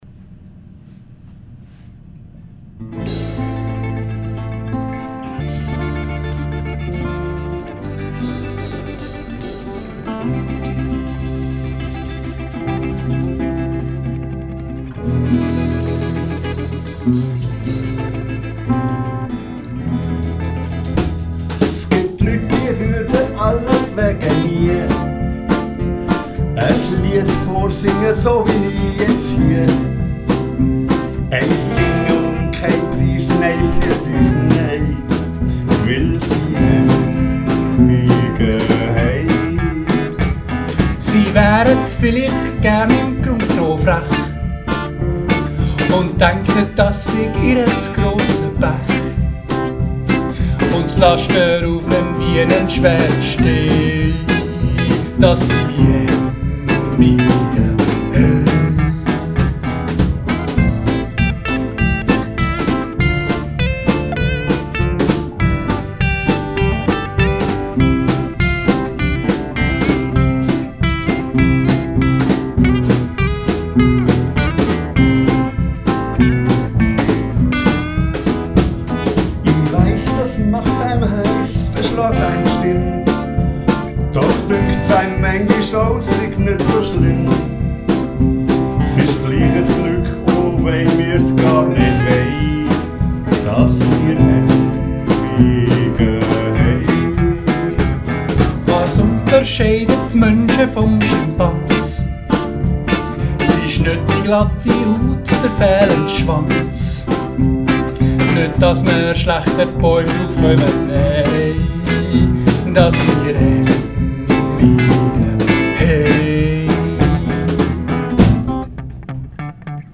wir sind aber etwas langsamer unterwegs